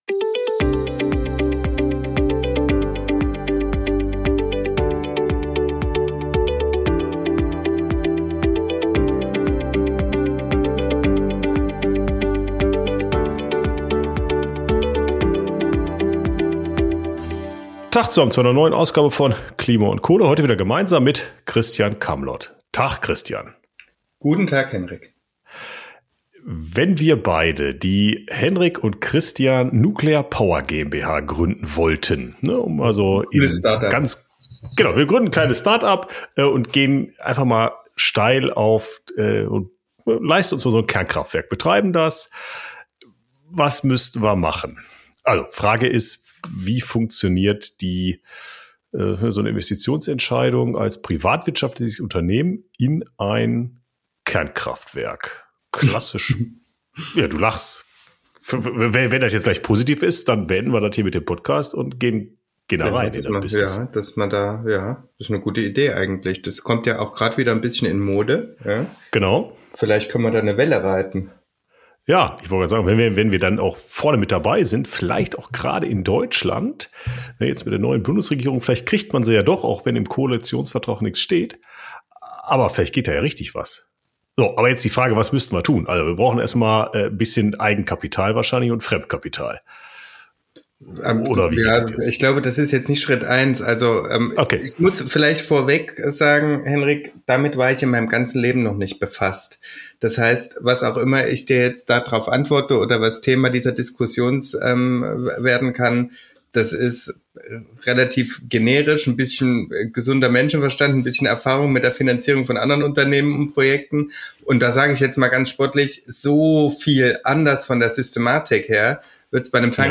#158 Wie finanziert man ein Kernkraftwerk? Gespräch